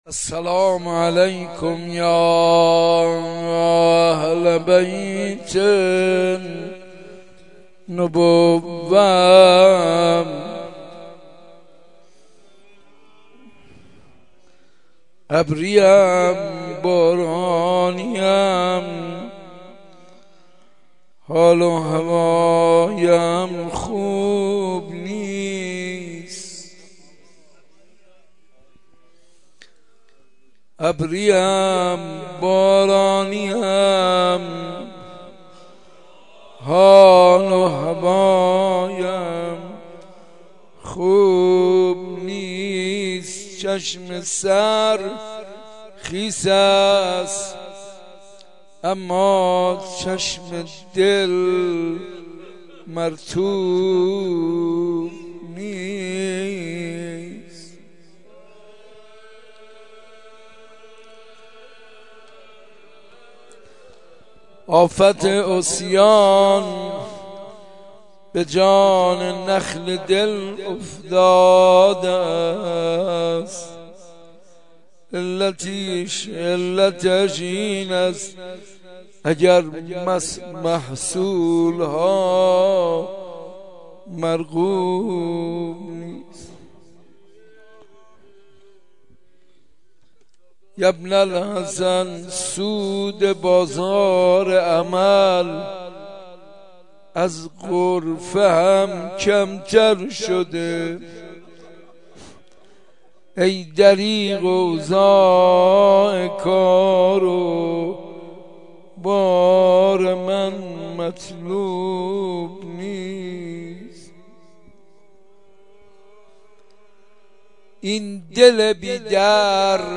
مناجات با حضرت حجةبن الحسن العسکری (عج)